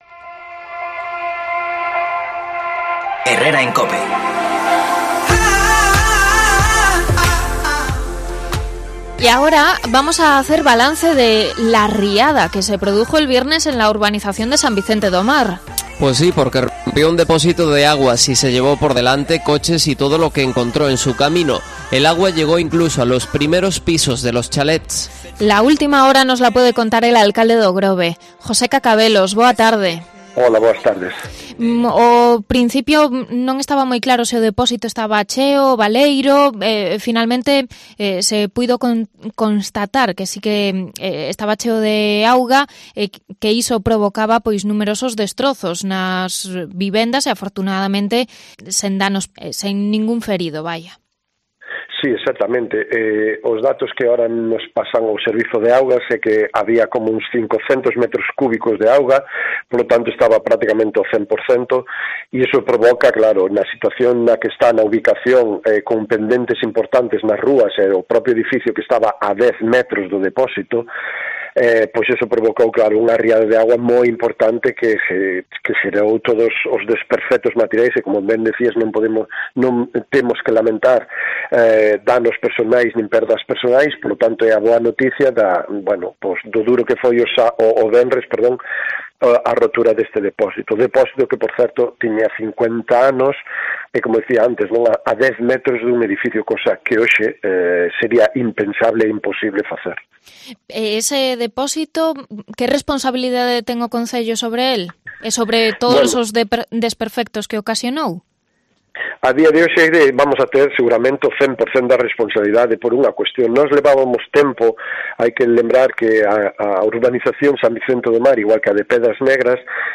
Entrevista al alcalde de O Grove sobre la urbanización de San Vicente do Mar